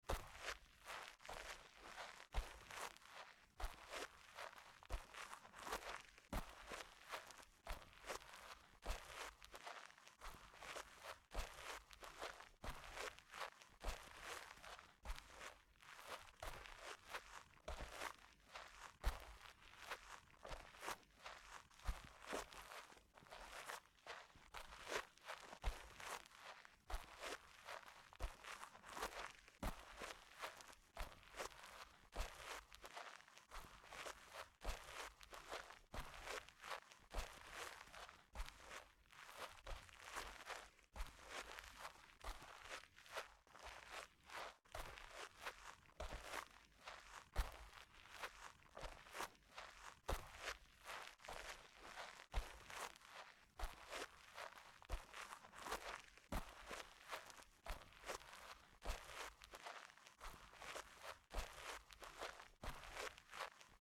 Шаги босыми ногами по ковру